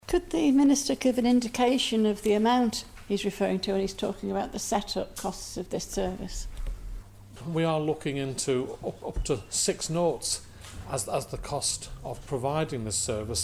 However Minister Howard Quayle says it will all come down to the ability to secure funding and the department isn't a "bottomless pit":
He was quizzed on the matter in the House of Keys earlier this week by South Douglas MHK Kate Beecroft who wanted to know how much needs to be spent: